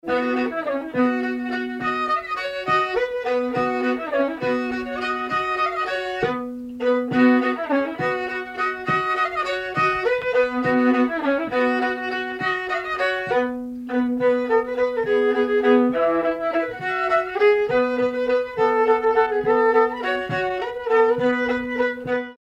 Valse
danse : valse
circonstance : bal, dancerie
Pièce musicale inédite